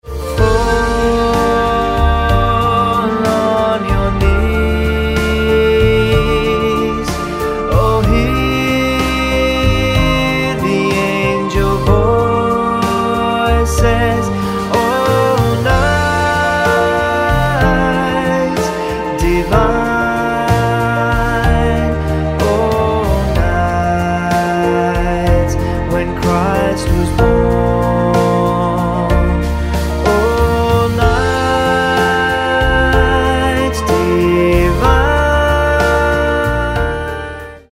Bb